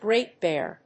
アクセントGréat Béar